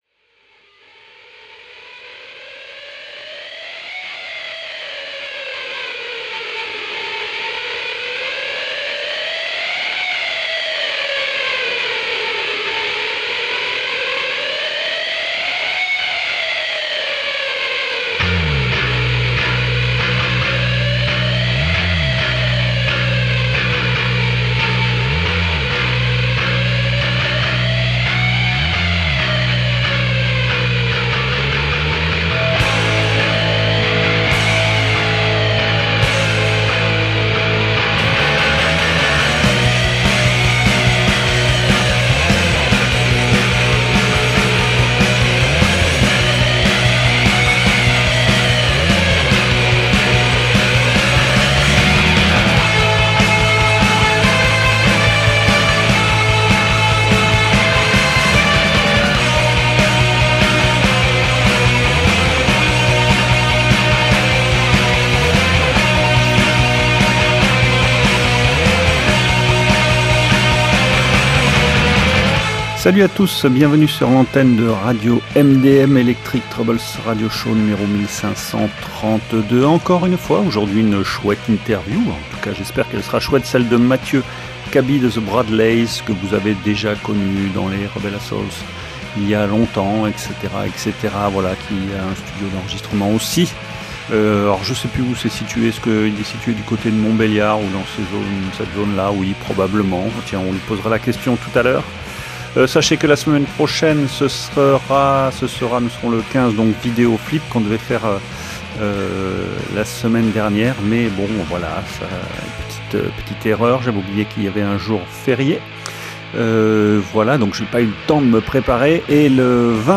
avec du riff qui tue et une rythmique façon uppercut.